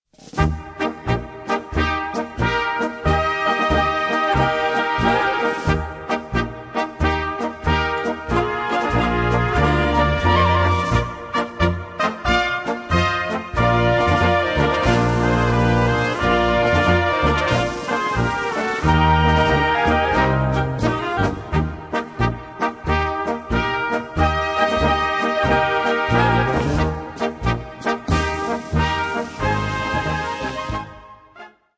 Gattung: Polka
Besetzung: Blasorchester